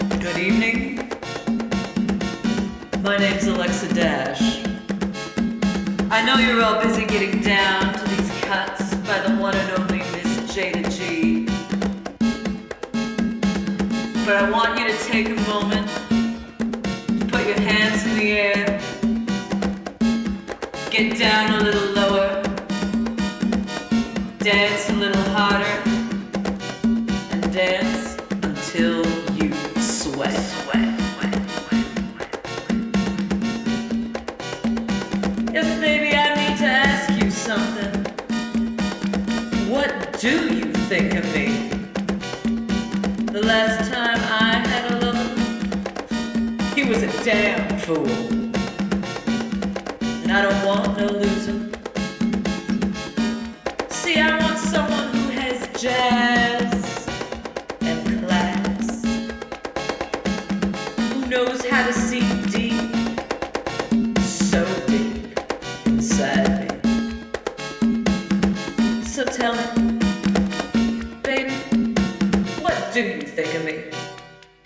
A three track remix package